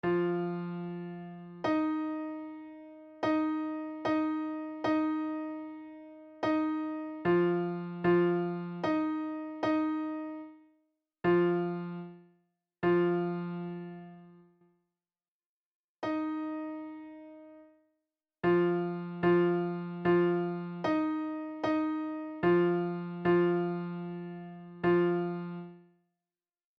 Intervals Minor 7th Exercise 02
minor7-02.mp3